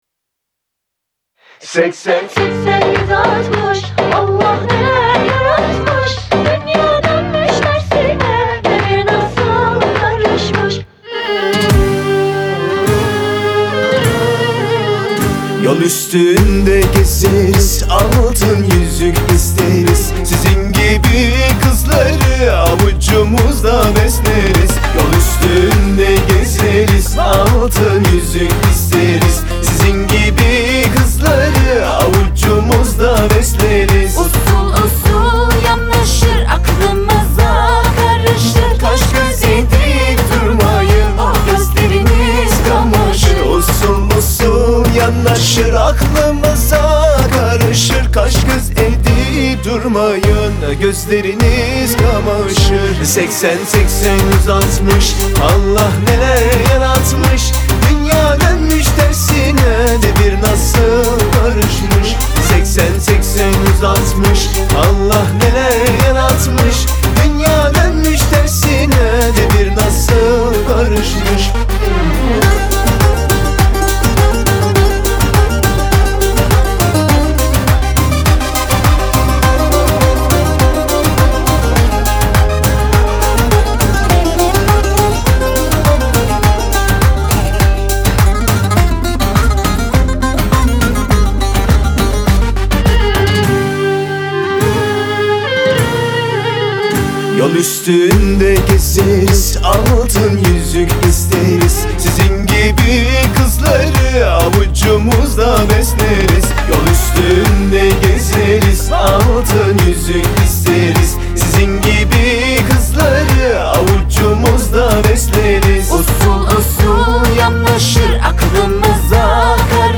Tür: Türkçe / Pop